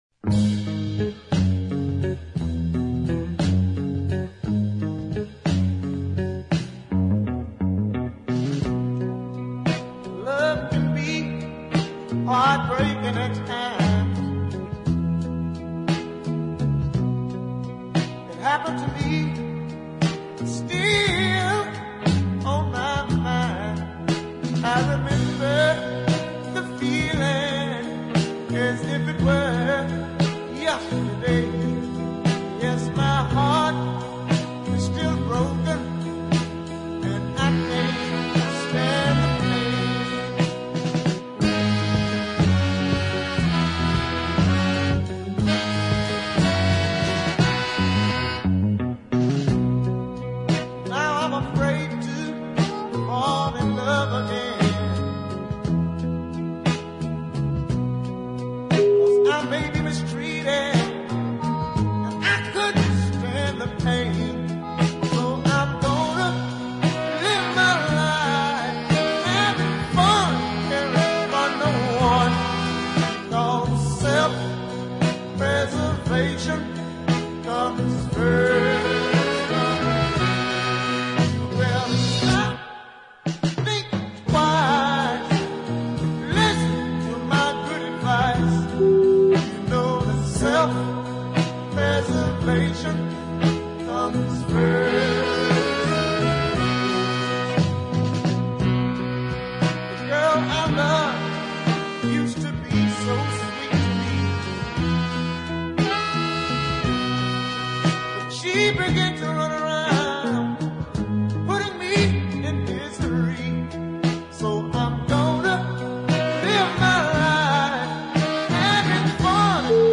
southern soul
a deep soul classic, a beautifully realised country ballad